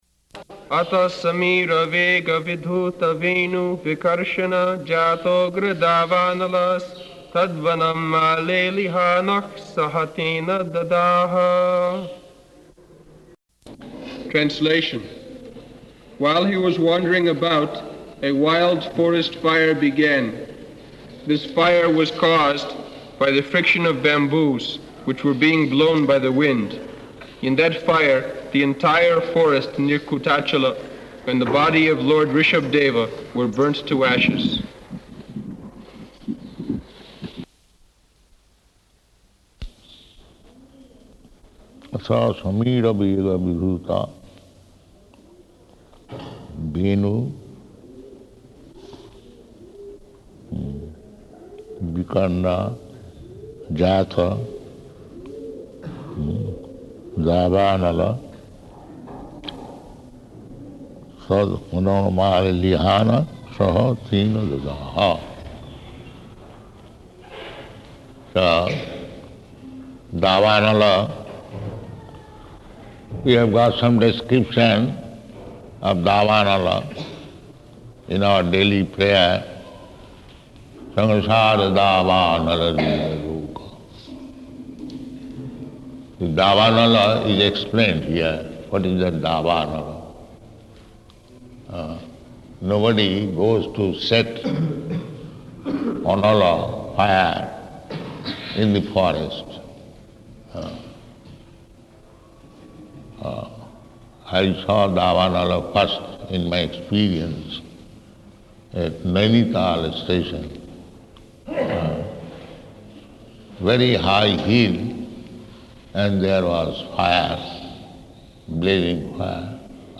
Type: Srimad-Bhagavatam
Location: Vṛndāvana